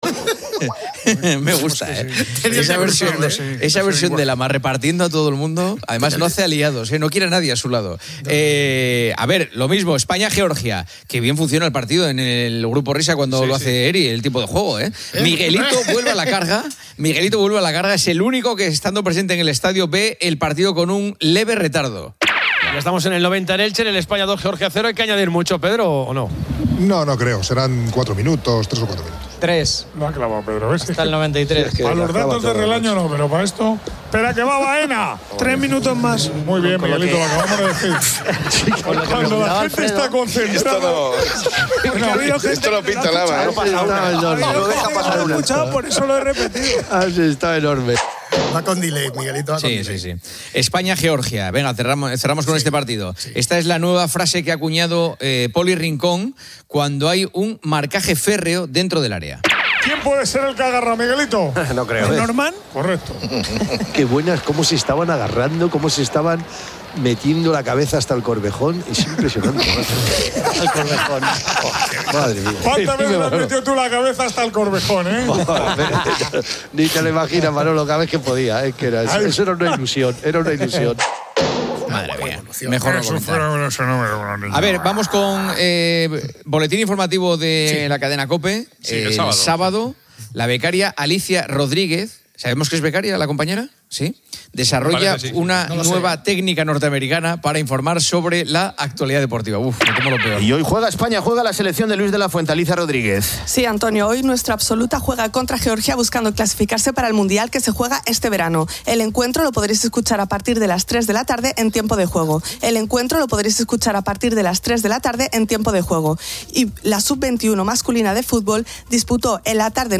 Se destacan momentos cómicos y errores en directo, así como la tertulia de "El Partidazo de COPE" y el inicio de "Poniendo las calles" con Carlos Moreno "El Pulpo".